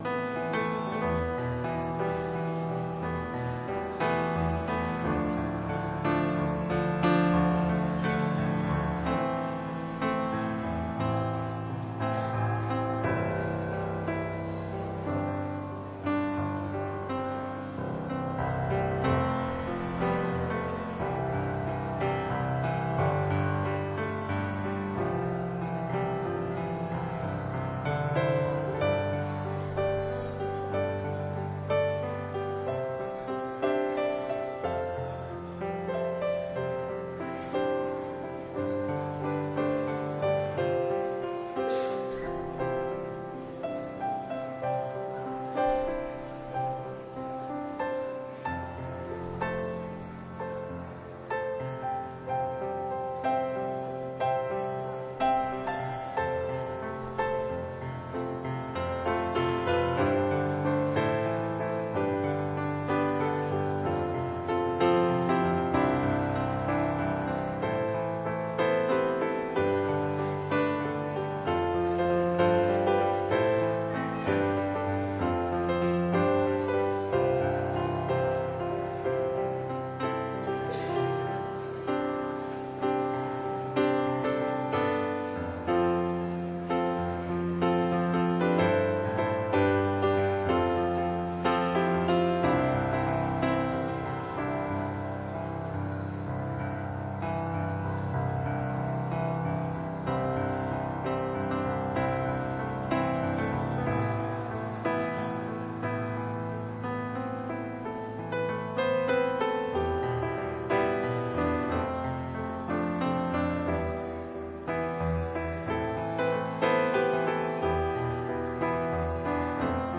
Kerkdienst terugluisteren